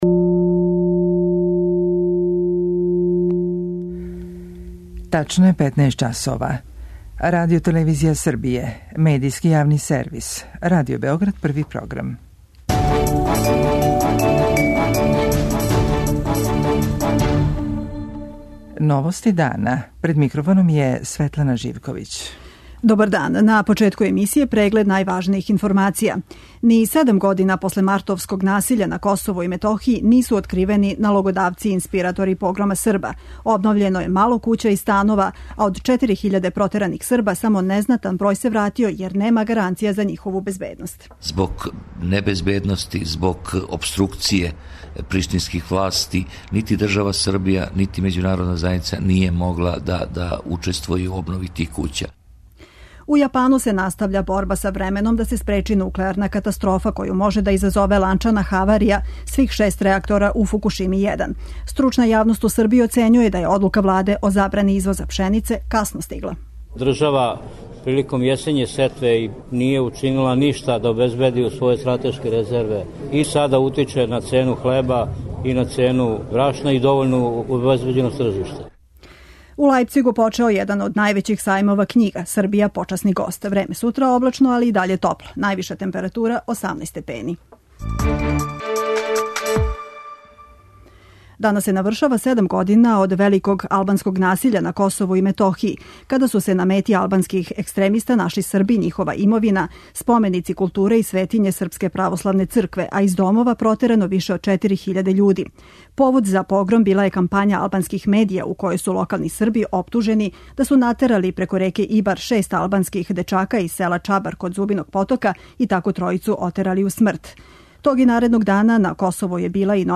За Радио Београд 1, о годишњици погрома српског становништва на Косову, говори министар за КиМ Горан Богдановић.